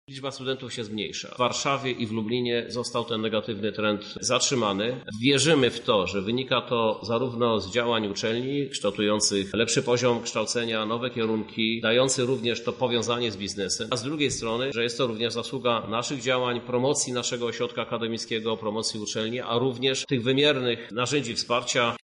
-Ważna dla Lublina jest jego akademickość – mówi prezydent miasta Krzysztof Żuk: